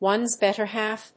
アクセントone's bétter hálf